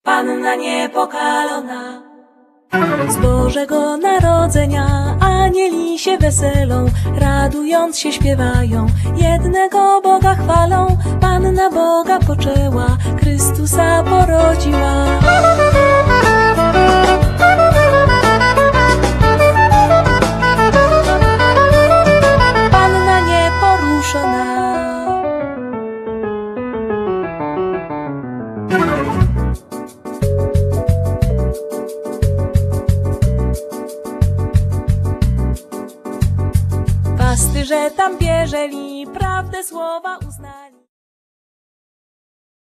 instrumenty klawiszowe, kontrabas, sample, loopy
altówka
perkusja
saxofon alt/tenor; flet poprzeczny alt